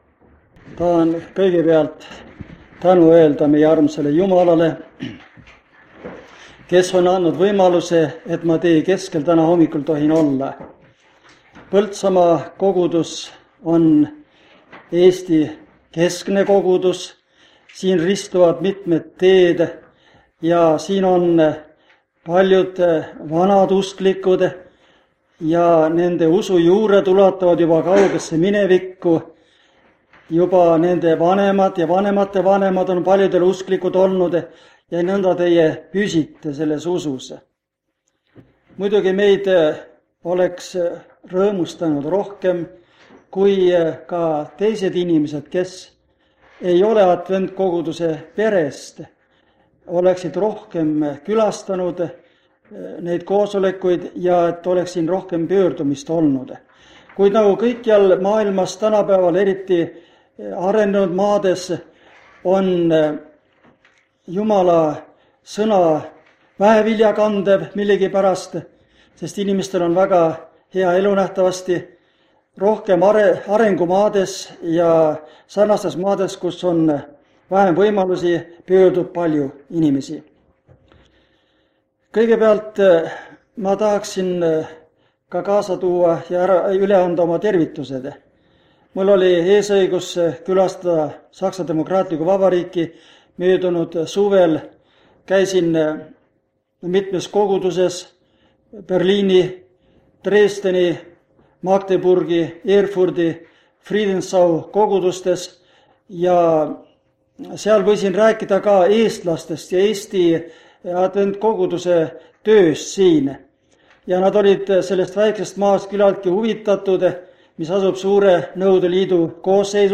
Jutlused
Jutlus vanalt lintmaki lindilt.